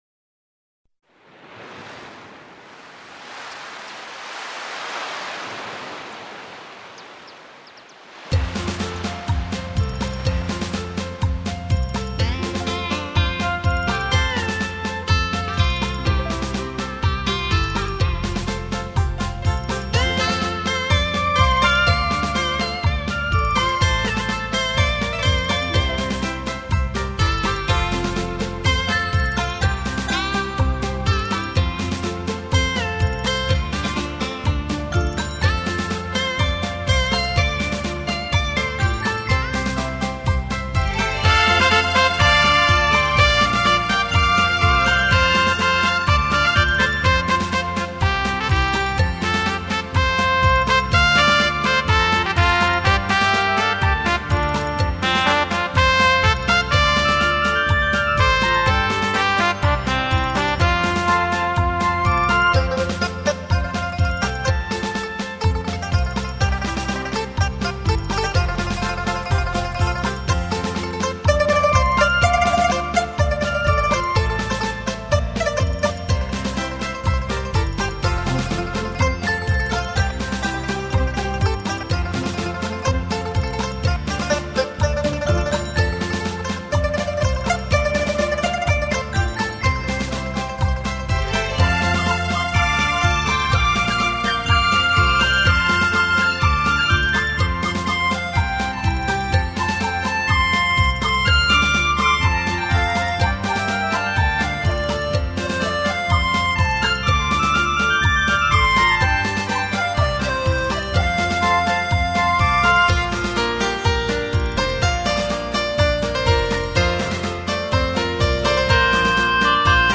[26/12/2008]舞曲精华单曲之4《浪花里飞出欢乐的歌》（伦巴）！